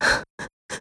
Ripine-Vox_Sad-02.wav